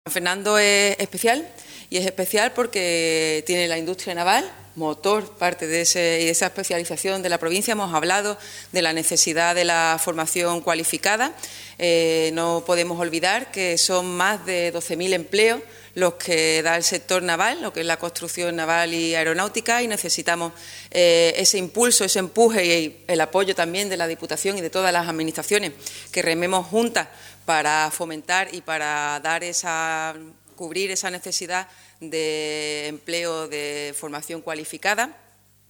En el curso de una atención a los medios de comunicación, Cavada ha incidido en la necesidad de que la Diputación, como ente supramunicipal, impulse las políticas estratégicas de la provincia.